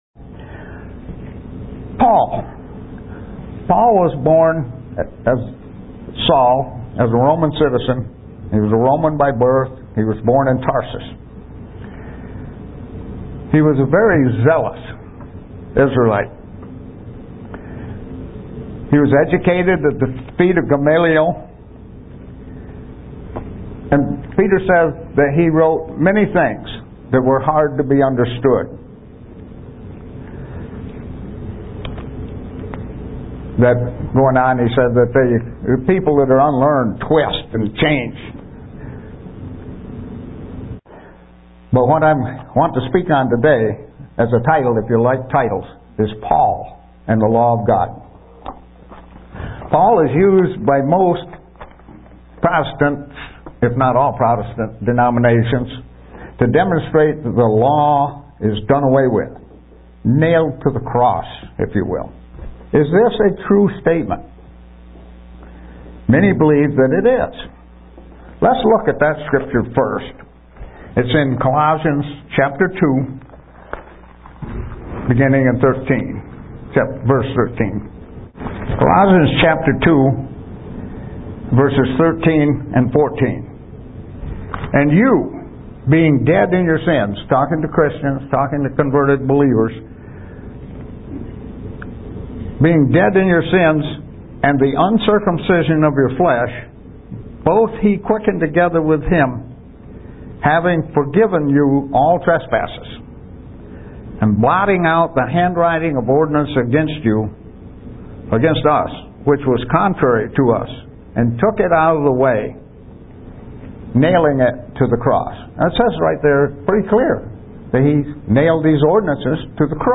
UCG Sermon Studying the bible?
Given in Buffalo, NY